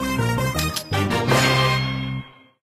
laser_load_01.ogg